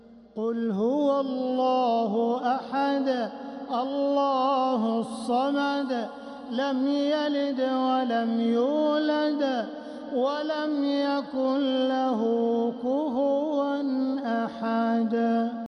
سورة الإخلاص | مصحف تراويح الحرم المكي عام 1446هـ > مصحف تراويح الحرم المكي عام 1446هـ > المصحف - تلاوات الحرمين